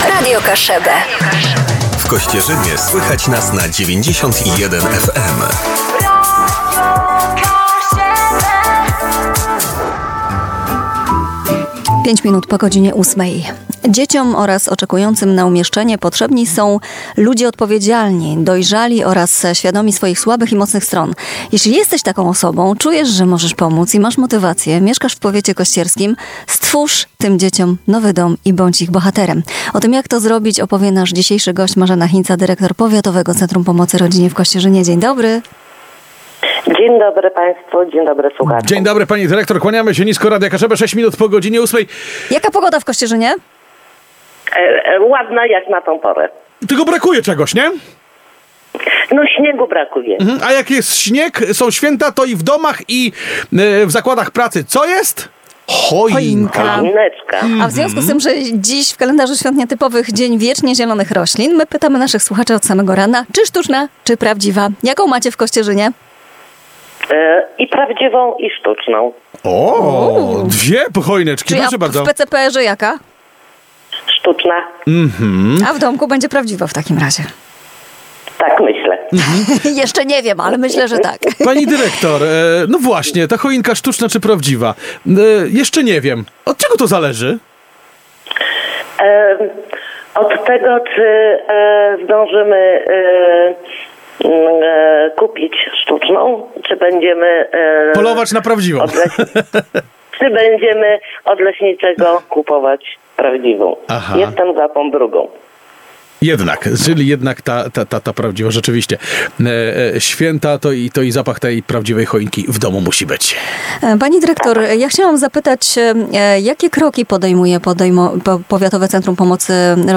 rozmowa_PCPRGKS.mp3